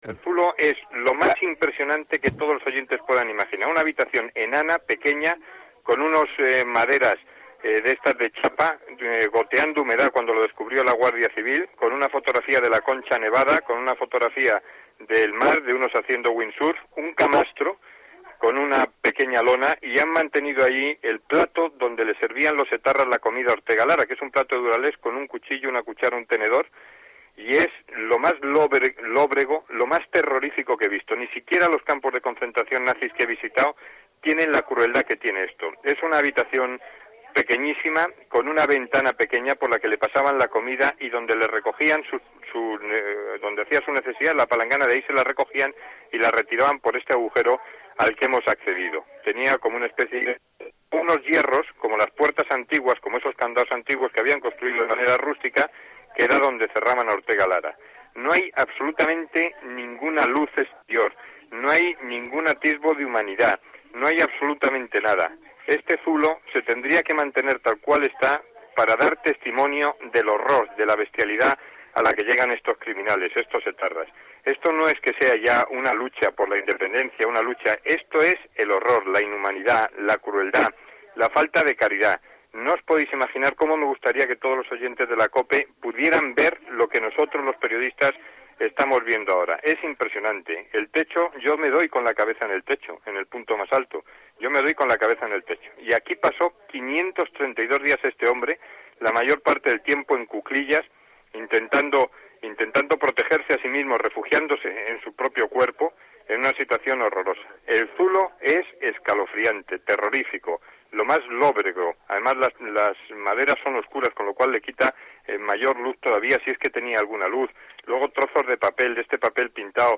El 7 de julio de 1997 Antonio visita el zulo en el que Ortega Lara permaneció secuestrado más de 500 días.
Antonio Herrerro describe el zulo de Ortega Lara